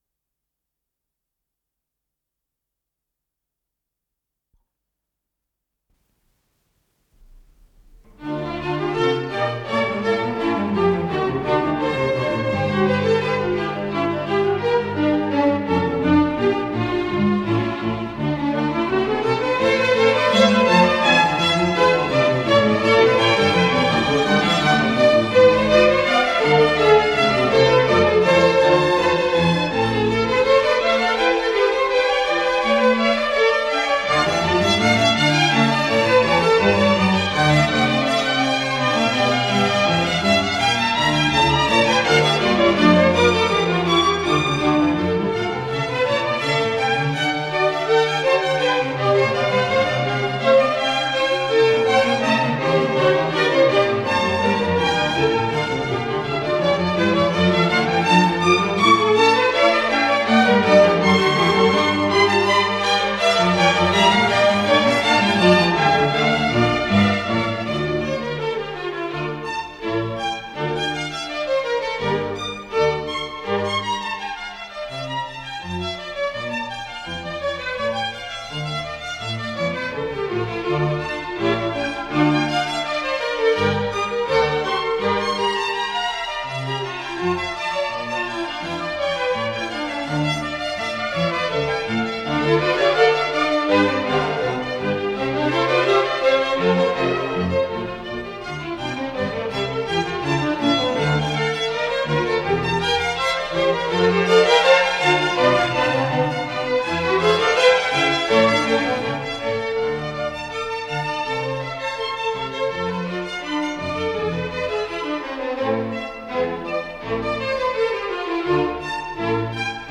с профессиональной магнитной ленты
Ансамбль солистов Большого симфонического оркестра Всесоюзного радио и Центрального телевидения
Скорость ленты38 см/с